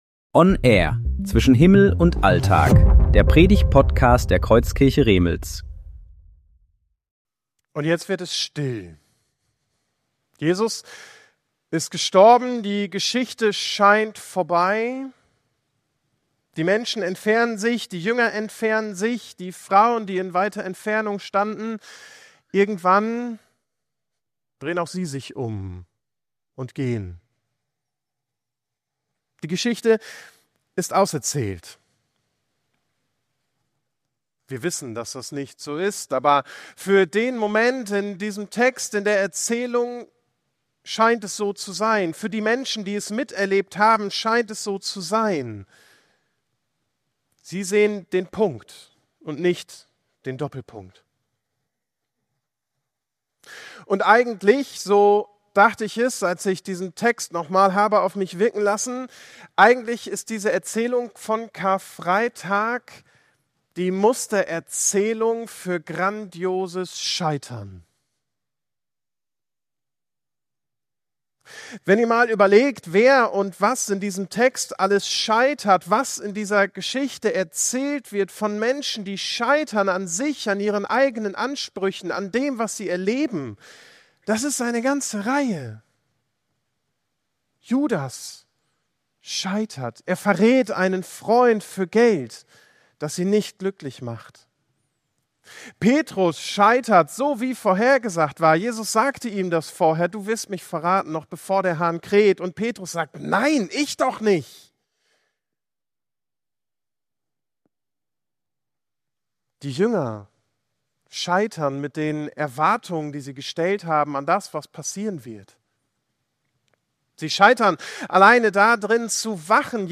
Predigtserie: Gottesdienst